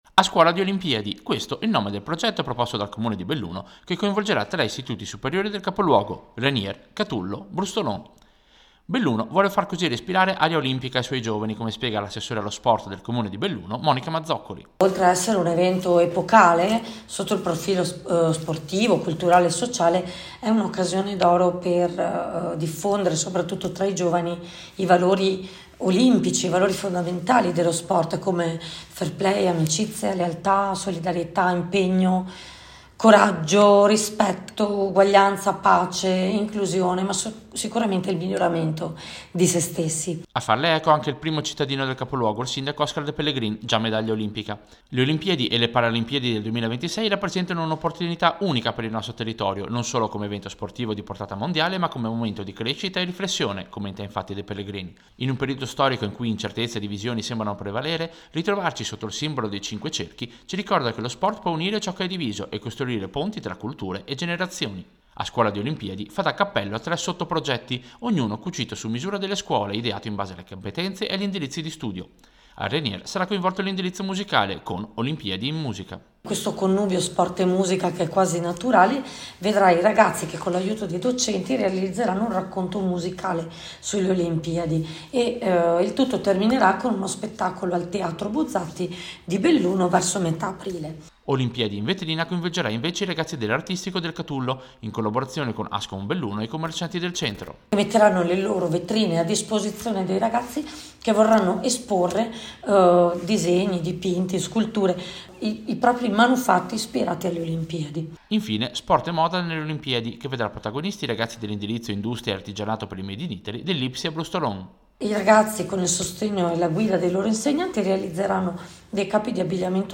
Servizio-A-scuola-di-Olimpiadi-Belluno.mp3